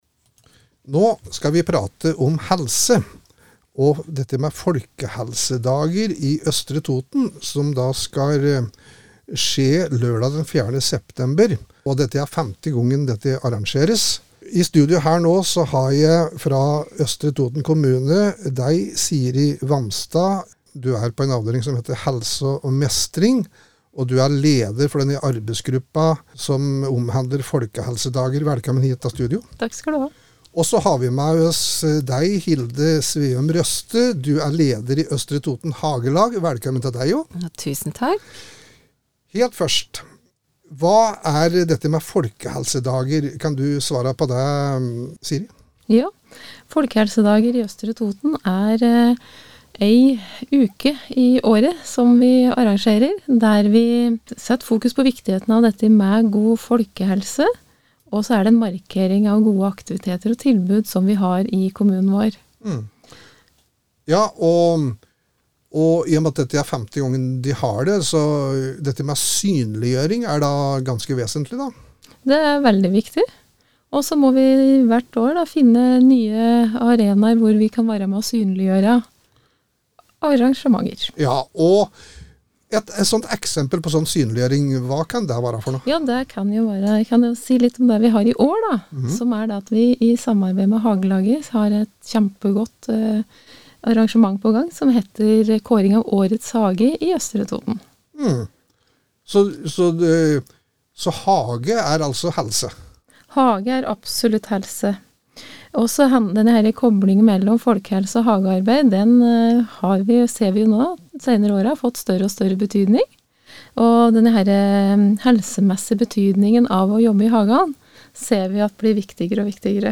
Vi har hatt besøk i studio.